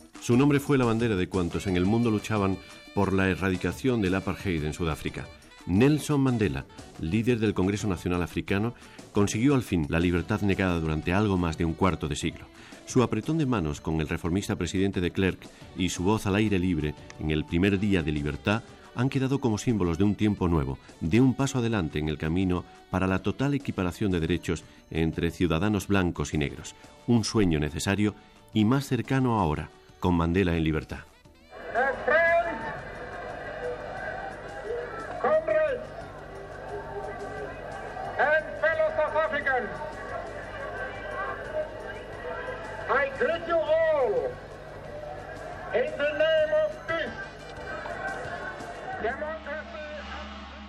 Informatiu
Lobatón, Paco